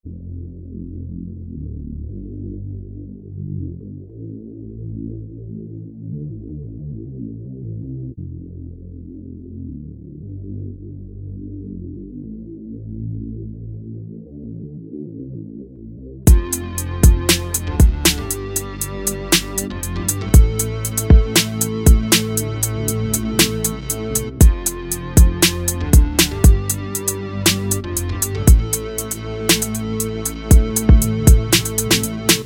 Wie findet ihr die drums dazu?? was würdet ihr für drums programmieren. hört es euch an und schickt mir eure ideen